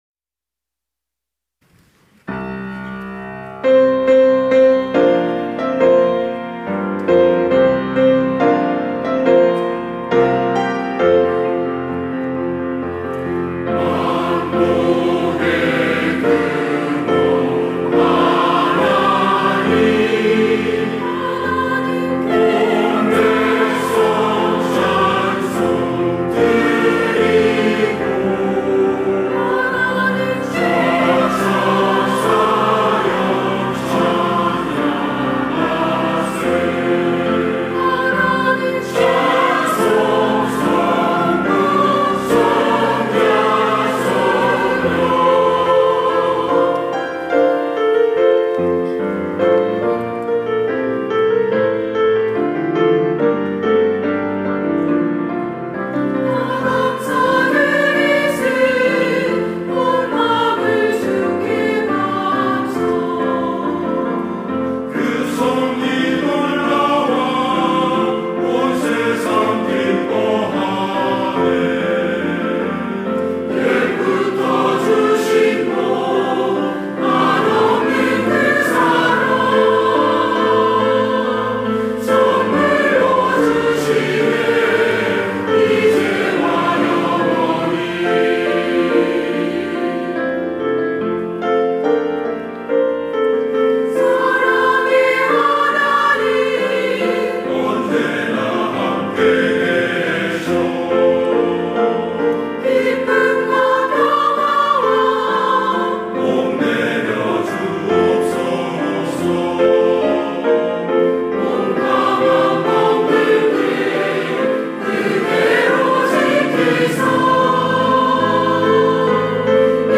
시온(주일1부) - 우리 하나님께 다 감사드리자
찬양대